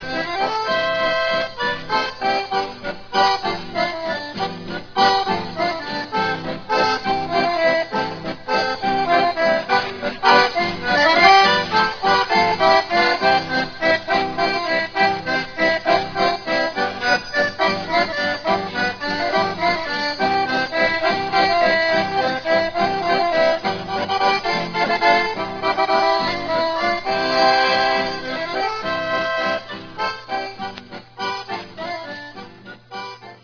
Acordeón
Pequeño acordeón de fabricación comercial, hecho por la casa Britta Hohner. Teclado de botones para ambas manos: veintiuno para registros melódicos y ochos para bajos.
Grabación: Verdulera, Vals
Ensamble: Acordeón y guitarra
Procedencia, año: Paraje Yohasá Depto. Santo Tomé, Prov. Corrientes, Argentina, 1978